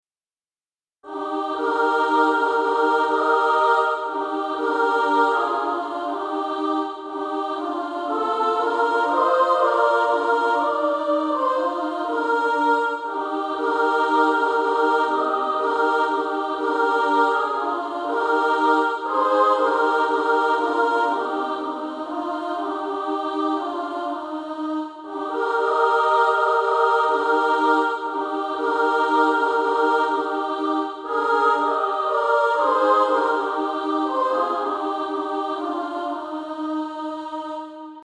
And also a mixed track to practice to
Practice then with the Chord quietly in the background.